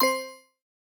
Coins (12).wav